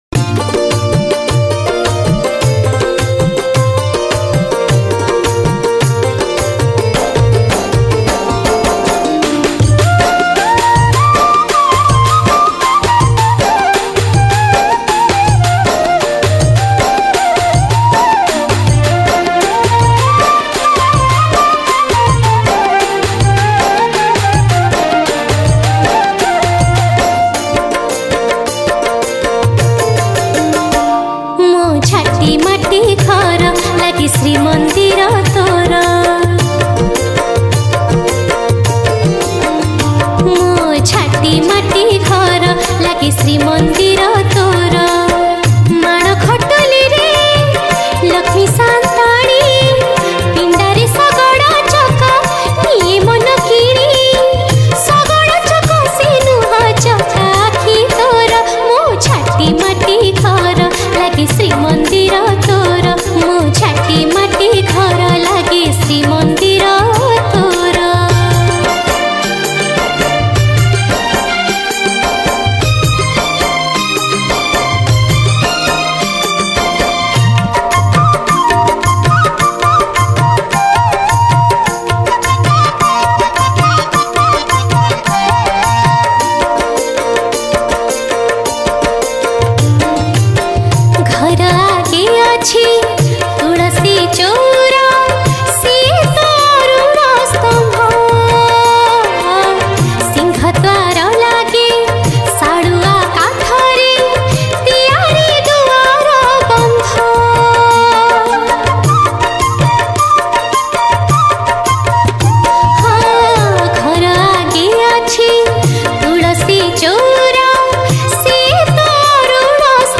Category: Odia Bhakti Hits Songs